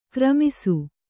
♪ kramisu